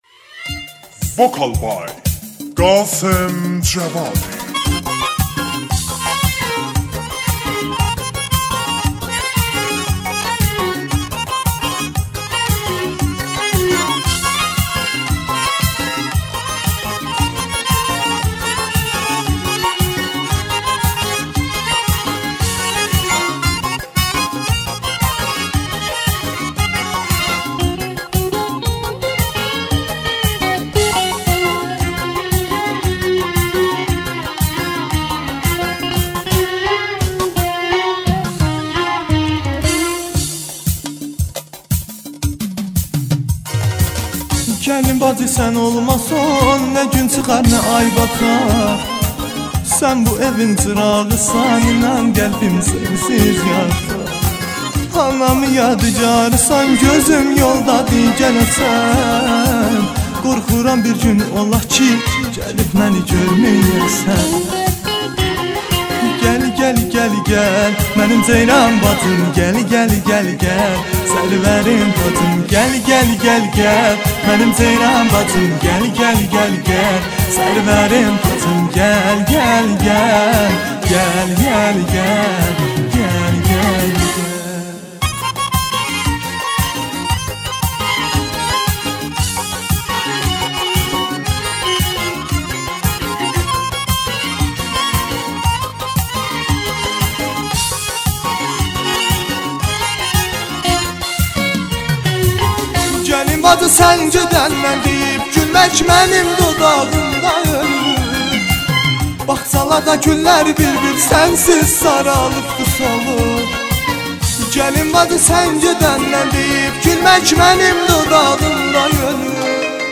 دسته بندی : آهنگ ترکی تاریخ : پنج‌شنبه 22 سپتامبر 2022